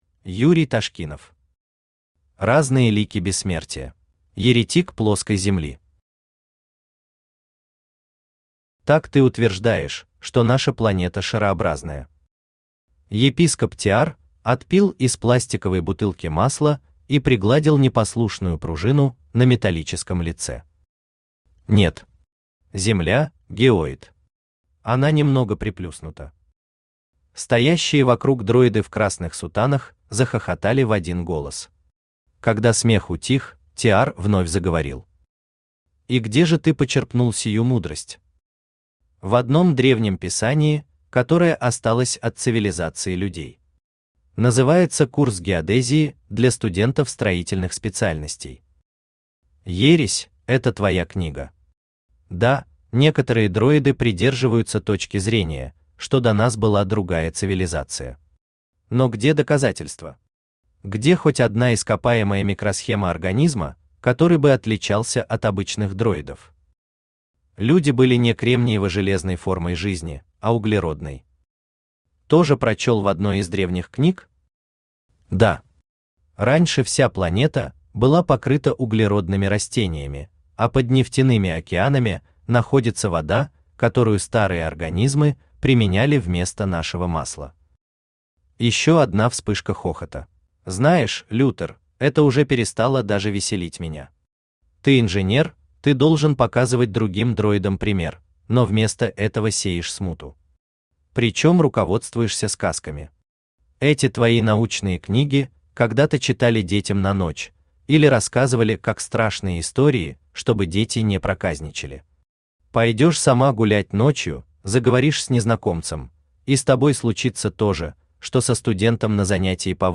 Аудиокнига Разные лики Бессмертия | Библиотека аудиокниг
Aудиокнига Разные лики Бессмертия Автор Юрий Андреевич Ташкинов Читает аудиокнигу Авточтец ЛитРес.